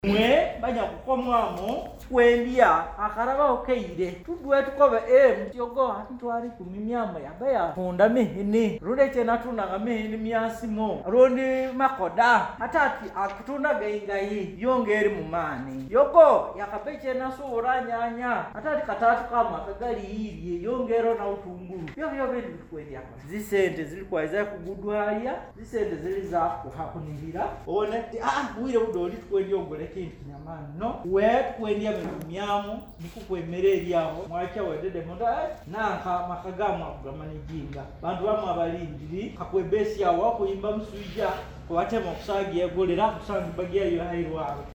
The support was handed over during a community engagement meeting held yesterday evening at Buliisa Community Hall , attended by district leaders, KAWIDA officials, and beneficiaries.